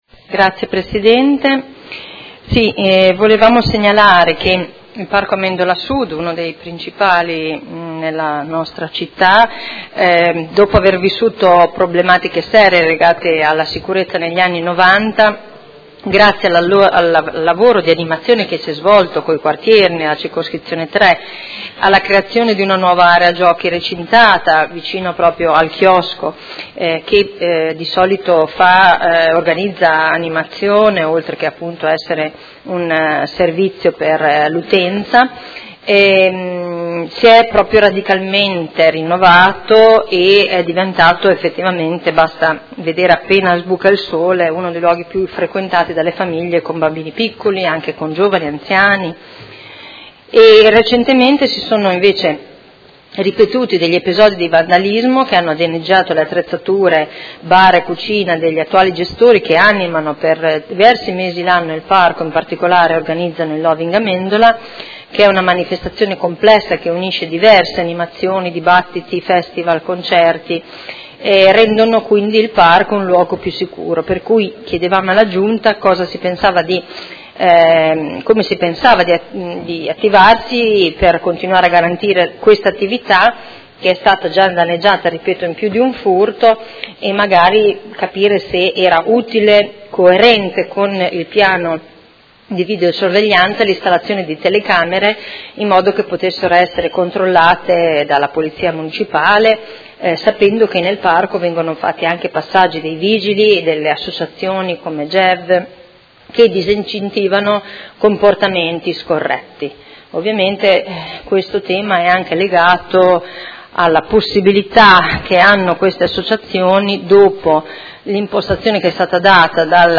Seduta del 19/04/2018. Interrogazione dei Consiglieri Arletti e Fasano (PD) avente per oggetto: Sicurezza al Parco Amendola: quali misure per evitare vandalismi nella struttura centrale a servizio del Parco?